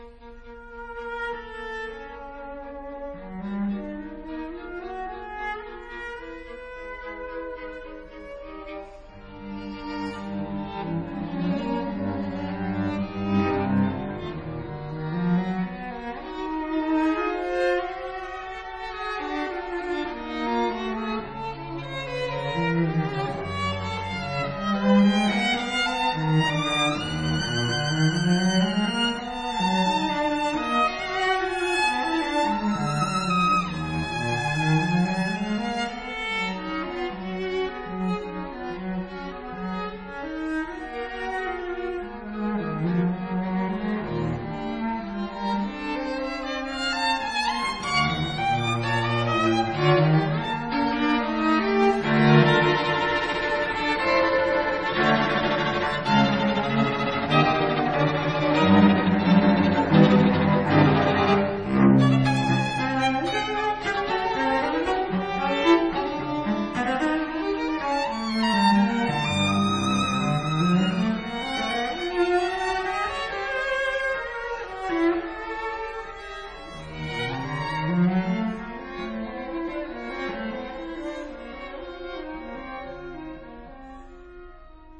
以及小提琴與大提琴的二重奏（試聽三），
她呈現了一種百年累積後，又開了小花的香味。